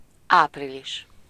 Ääntäminen
Ääntäminen France (Paris): IPA: /a.vʁil/ Tuntematon aksentti: IPA: /ɑ.vʁil/ Haettu sana löytyi näillä lähdekielillä: ranska Käännös Ääninäyte Substantiivit 1. április Suku: m .